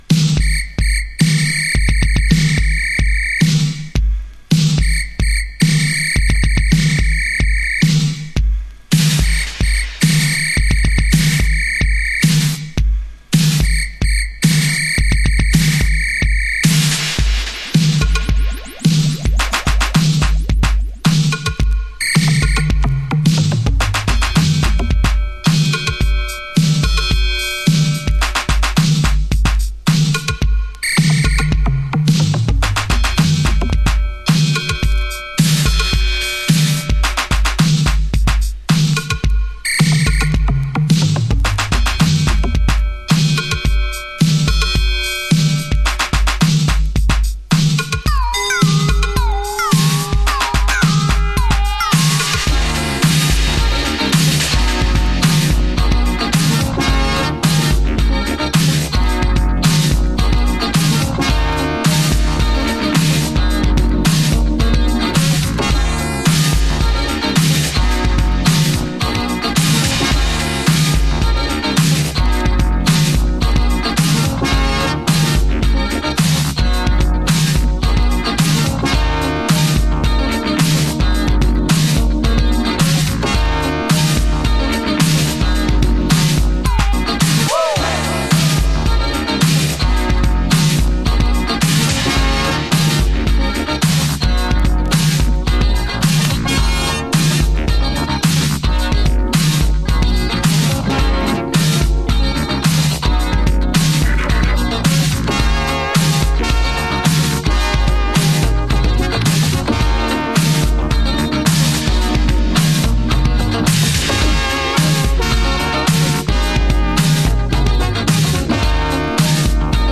Vox Mix